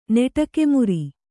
♪ neṭake muri